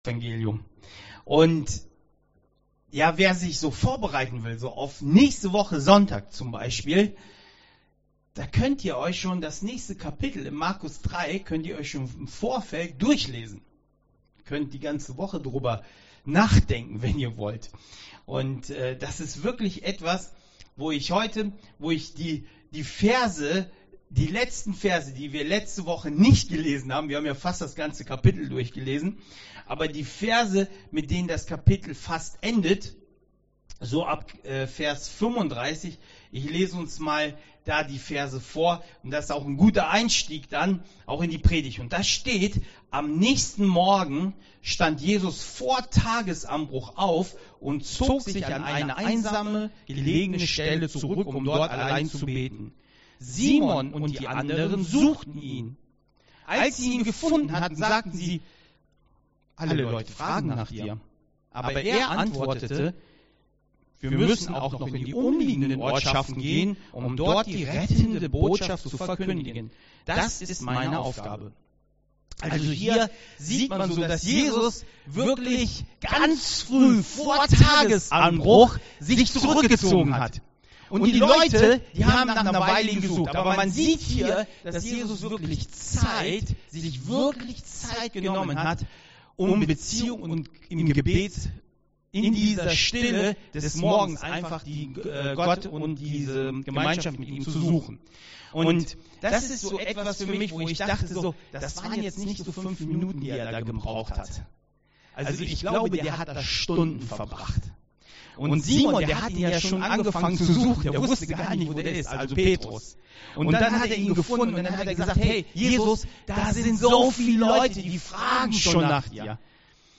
Predigt 18.04.2021